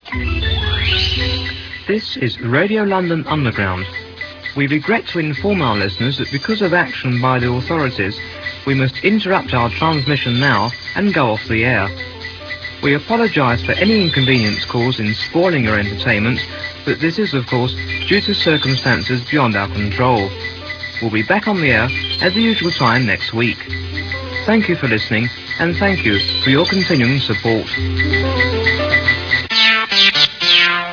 Click here to hear the announcement that would indicate to listeners that programmes must cease immediately due to an impending raid on the station.